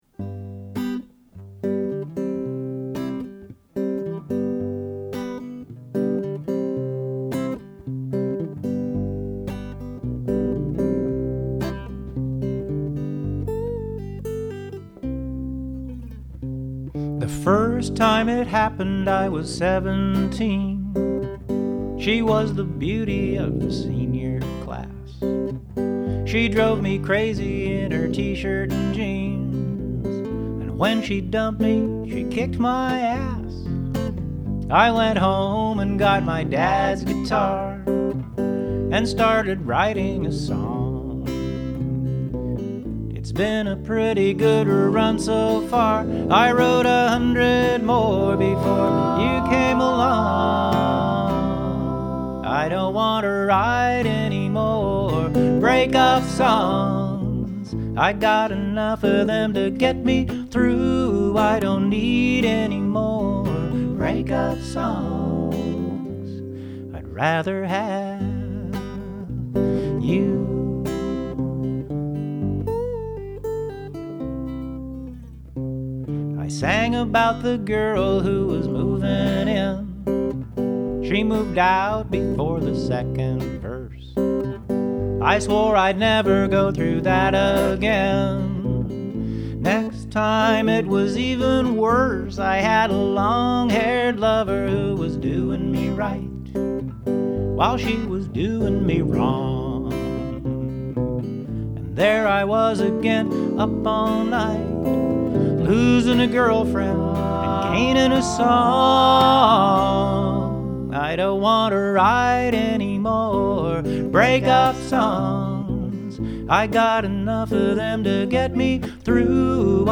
I keep working my way closer to recording in strict just intonation.
The acoustic guitar is in equal temperament, and the bass and vocals are untempered. I love singing harmony when the fretless bass is playing lattice notes.